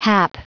Prononciation du mot hap en anglais (fichier audio)
Prononciation du mot : hap